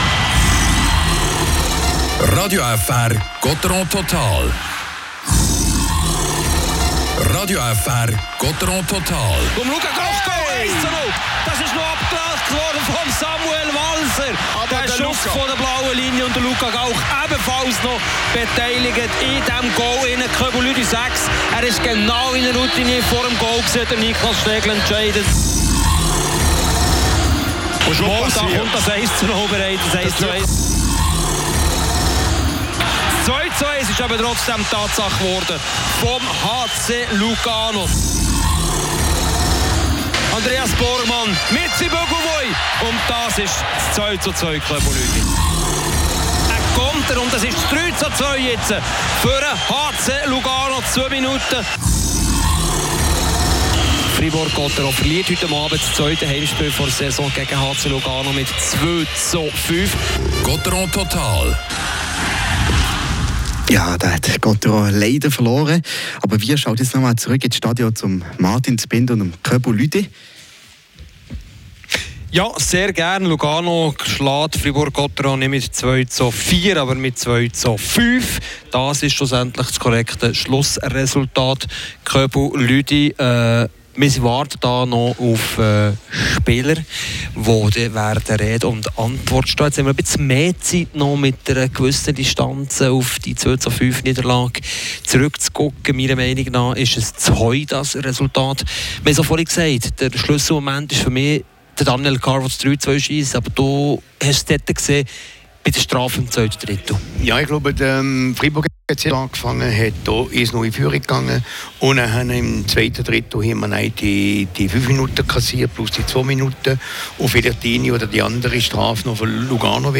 Interview mit dem Spieler Samuel Walser.